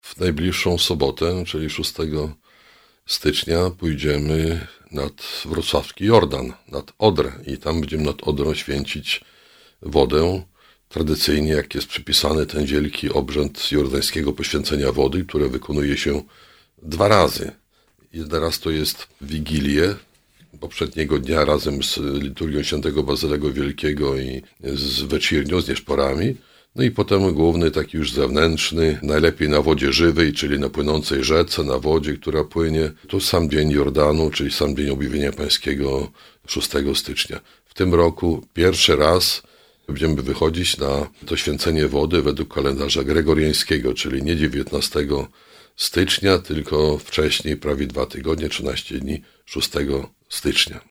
Zaprasza Bp Włodzimierz Juszczak, biskup eparchii wrocławsko-koszalińskiej.